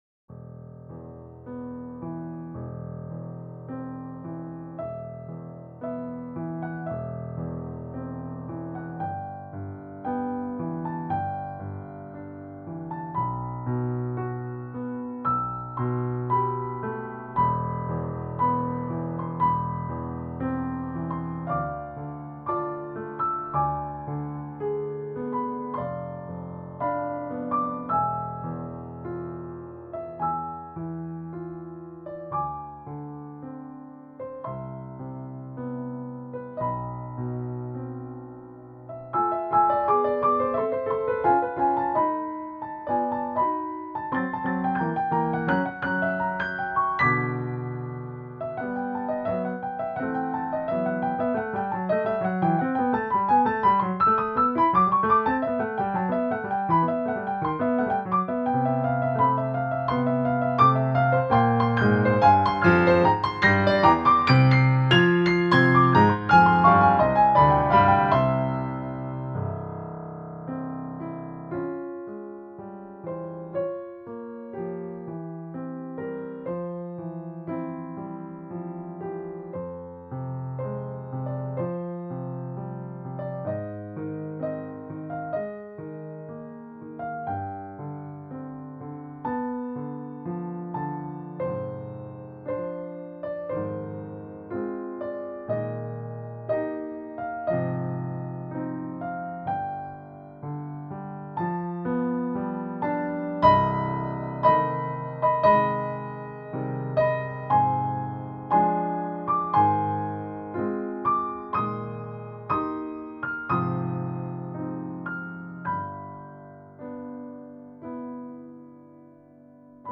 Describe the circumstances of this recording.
Should be better than the generated file.